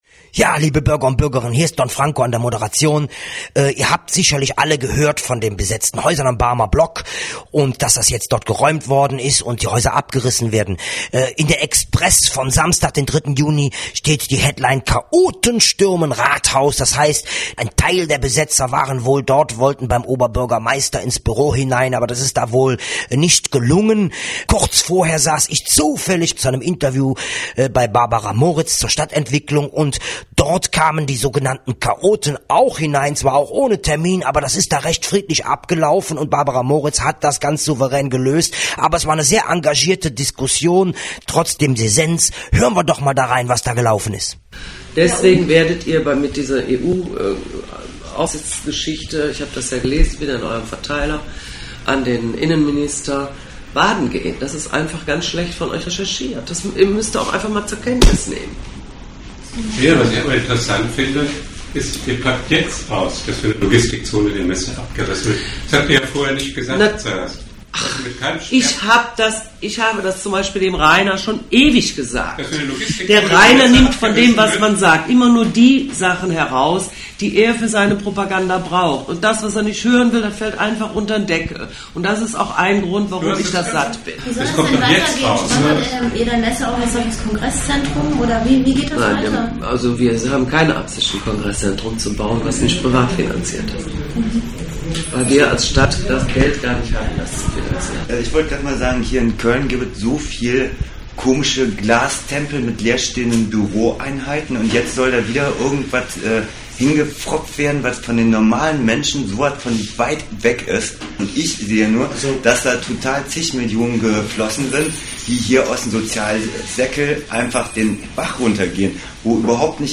Die NRhZ dankt RADIO FloK und der Redaktion VOX POPULI für diesen Beitrag, aber auch Barabra Moritz, die den Mitschnitt der engagierten Diskussion in ihrem Büro gestattet hat.